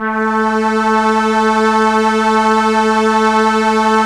Index of /90_sSampleCDs/Roland LCDP09 Keys of the 60s and 70s 1/STR_ARP Strings/STR_ARP Ensemble